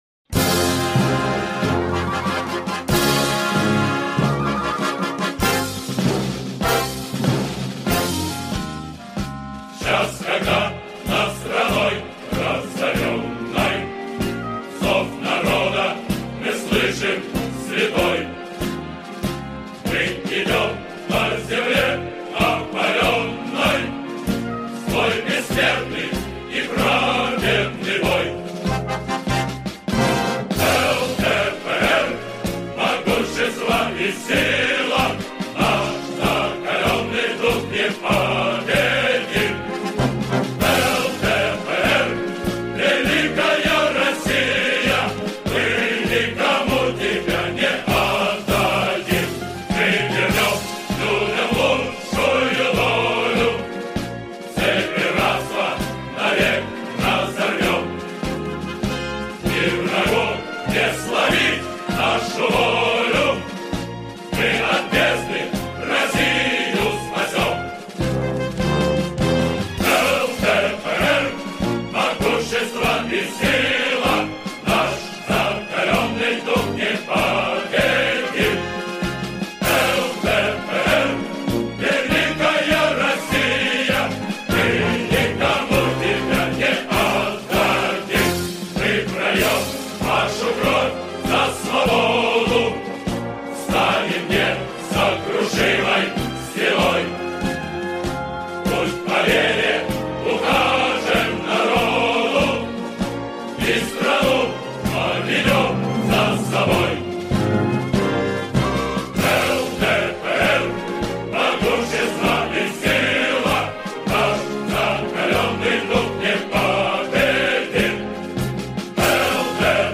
со словами